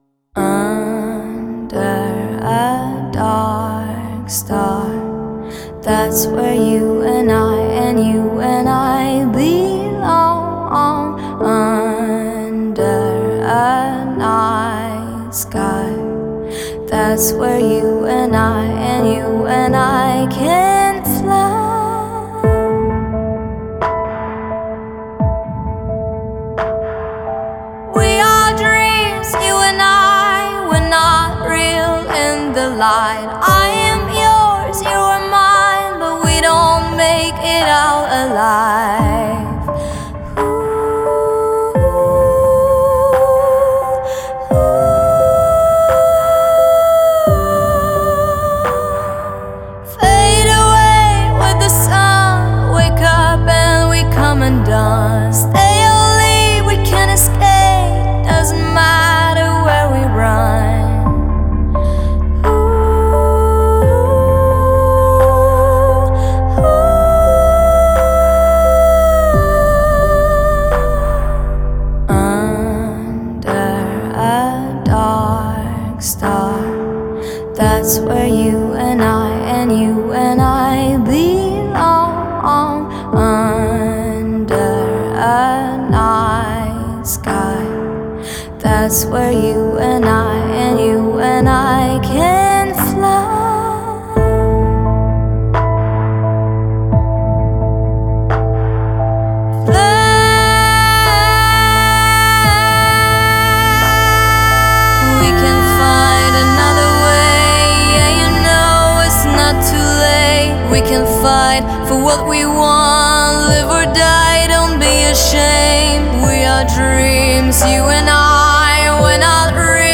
Genre: Pop, Female vocalists